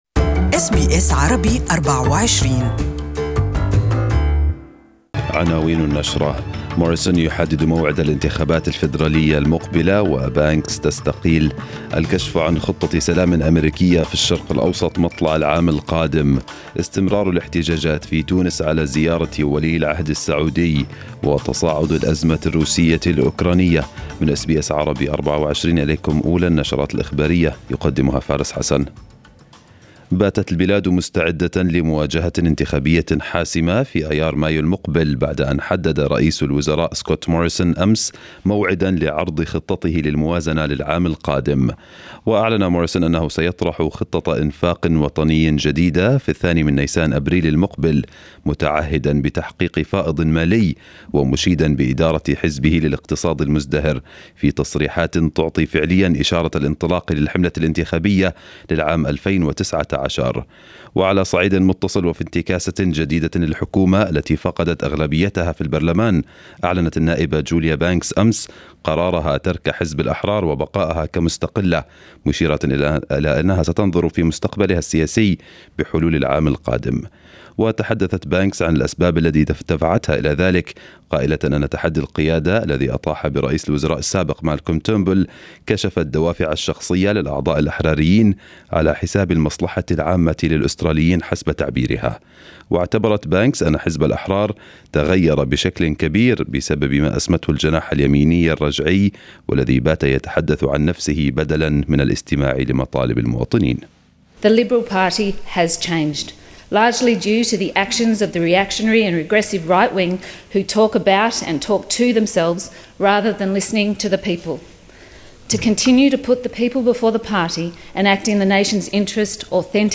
News bulletin of the day